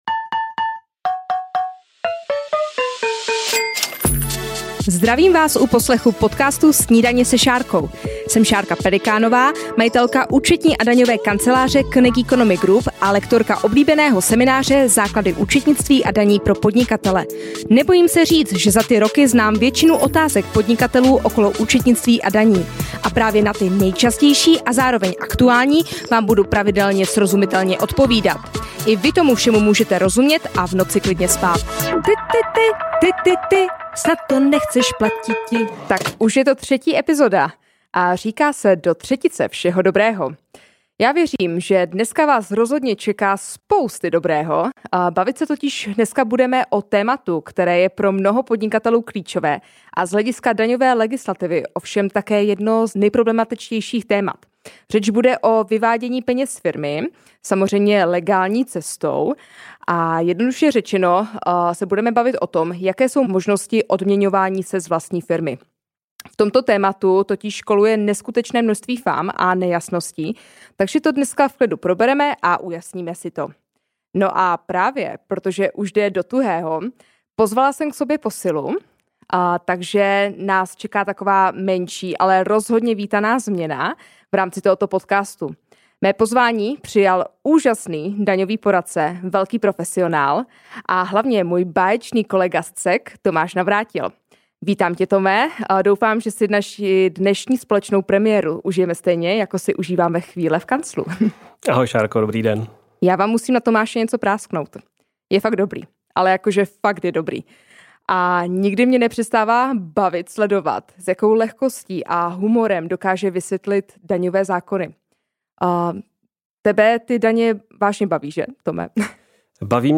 Do této epizody jsem si pozvala hosta